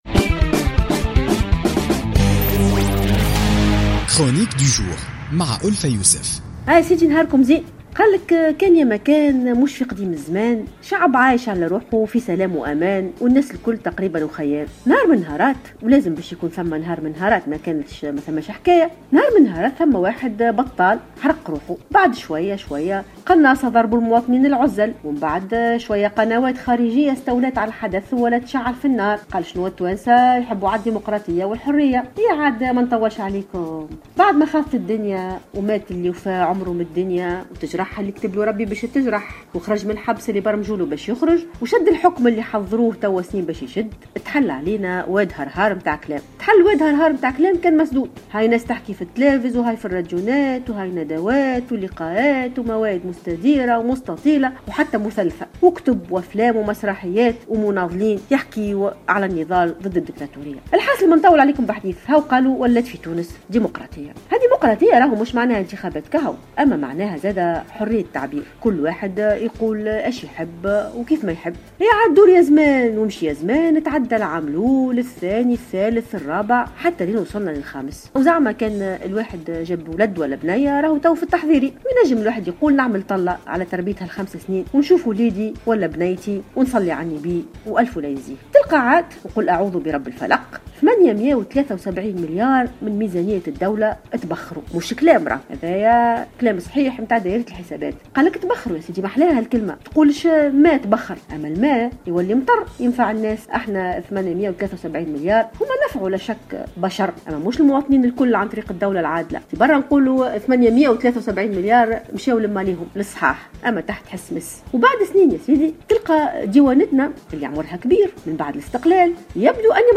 تطرقت الأستاذة والباحثة ألفة يوسف في افتتاحية اليوم الاثنين 22 فيفري 2016 إلى حادثة إطلاق سراح المستثمر "البلجيكي" والتي أثارت ضجة وحملة من الانتقادات والسخرية في صفوف التونسيين بعد أن صرح أحد المسؤولين في الديوانة بأن الأسلحة التي تم حجزها حقيقية وبأنهم كانوا يتابعون الحاوية قبل دخولها إلى البلاد ثم وقع التراجع عن هذه التصريحات والاقرار بأن الأسلحة مجرد لعب "بلاستيكية".